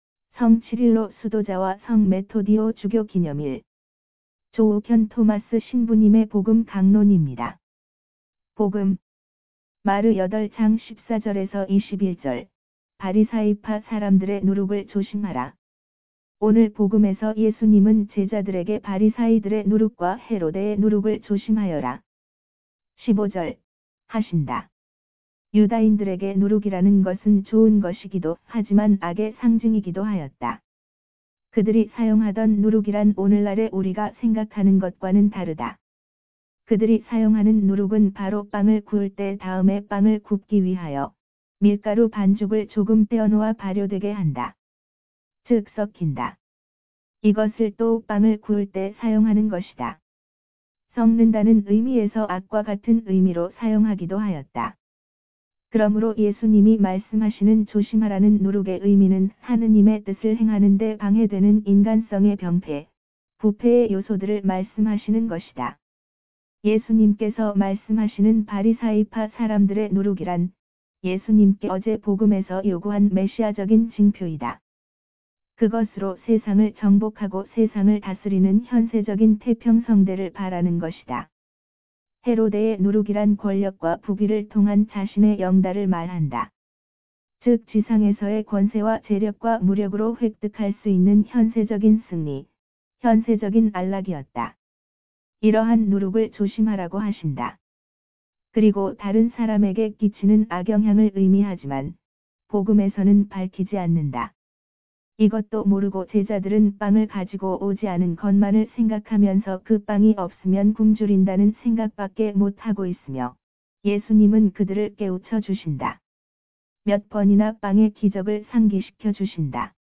강론